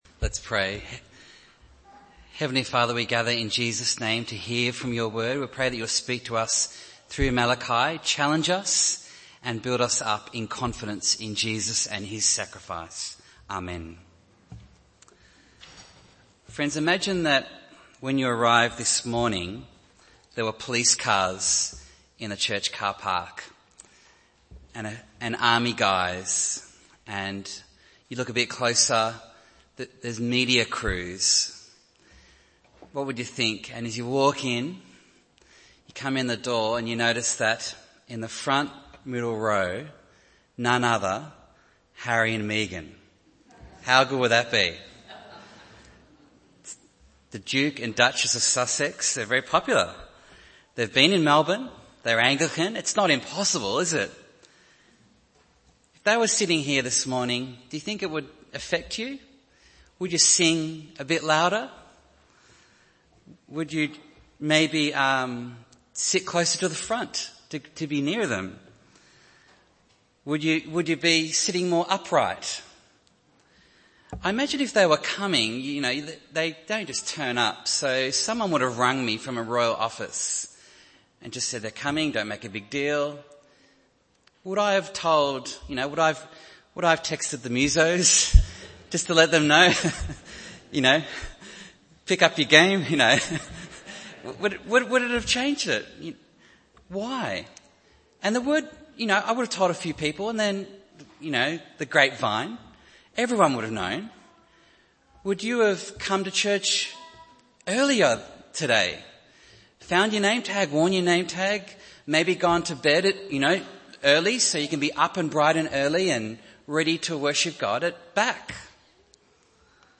Service Type: Sunday Morning Bible Text: Have We Given God Our Best?